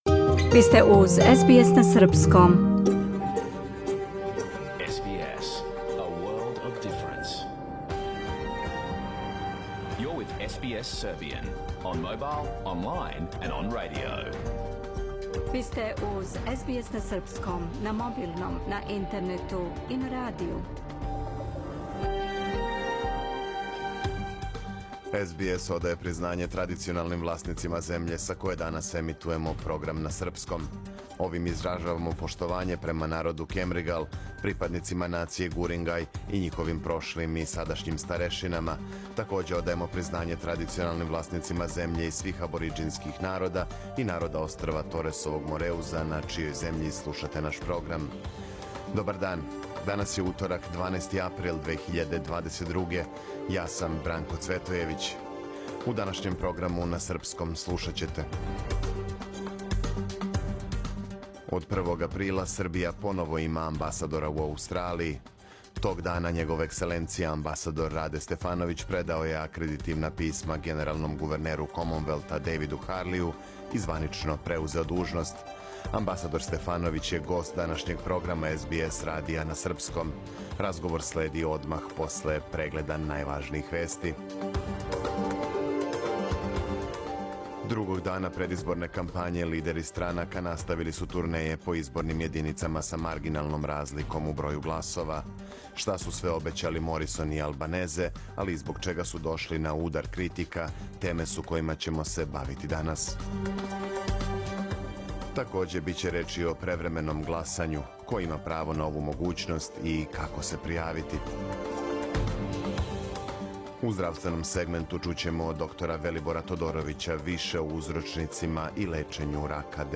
Програм емитован уживо 12. априла 2022. године
Амбасадор Стефановић је гост данашњег програма СБС радија на српском језику. Разговор следи одмах после прегледа најважнијих вести дана.